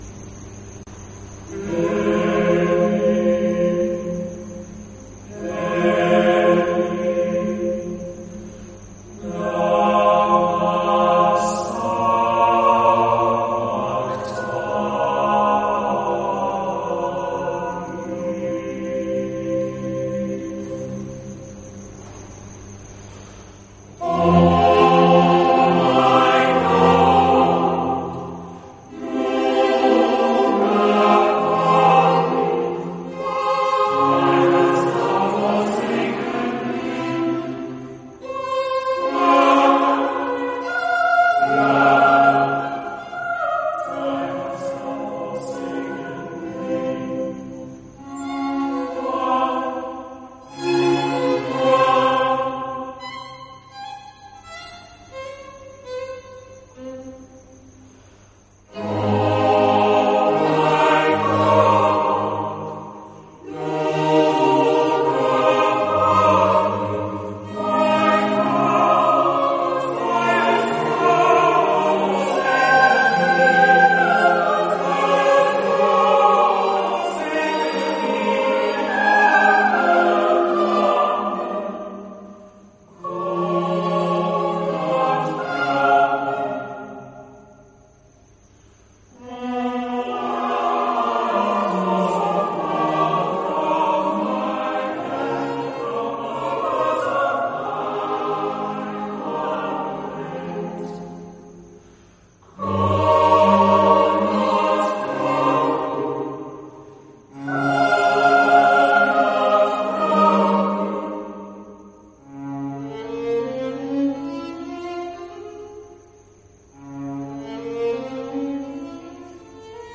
“My God, My God Why Have You Forsaken Me” from The Seven Last Words of Christ. Composed by Franz Joseph Haydn. Performed by The Chanticleer Singers.
Naturally, like Haydn’s somber composition, the darkness covering the land attests to the misery and looming grief of the moment.